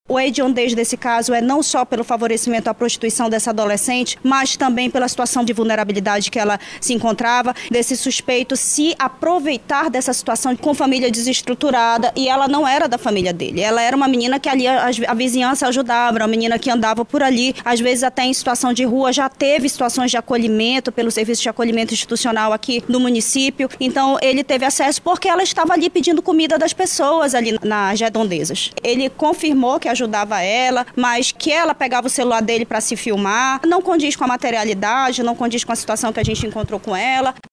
SONORA02_DELEGADA-2.mp3